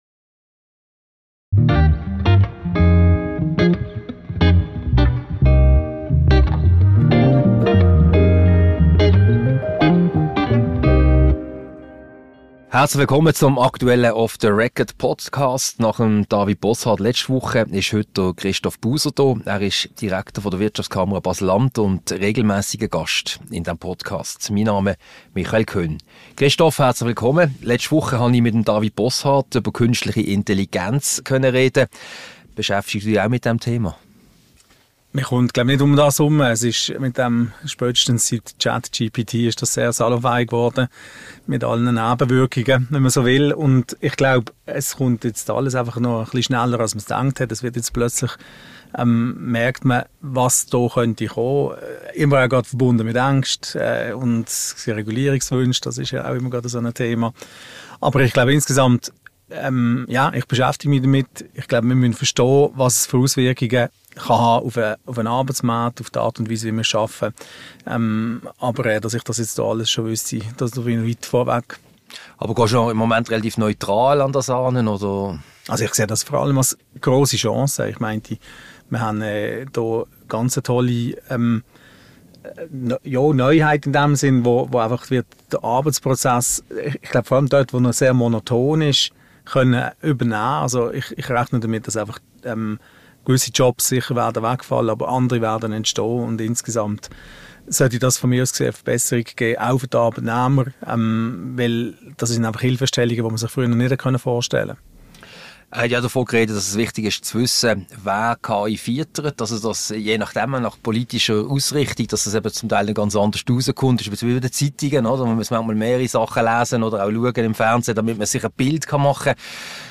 Ein Gespräch über aktuelle Themen wie künstliche Intelligenz, den Umgang von Behörden mit Unternehmen und den Klimaschutz.